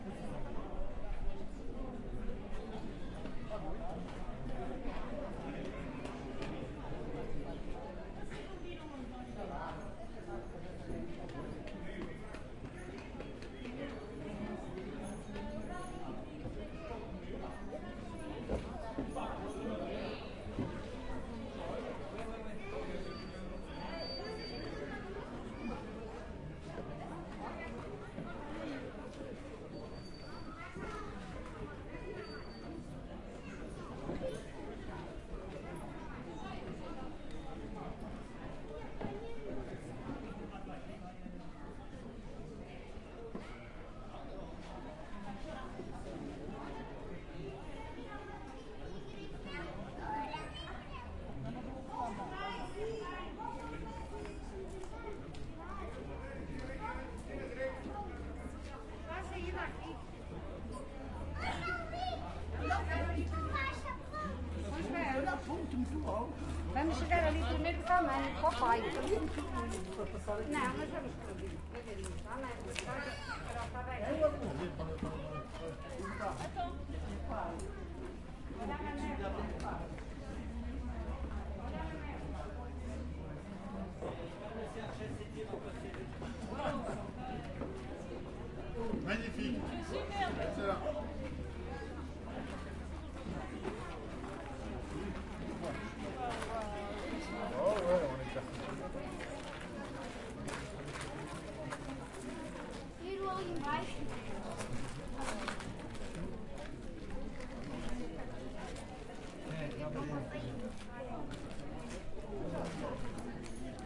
描述：录制于葡萄牙吉马良斯城堡的一个周日。旅游者用葡萄牙语交谈。城堡的墙壁提供了一个很好的氛围。
Tag: 音景 城堡 说话的声音 人群中 现场记录 葡萄牙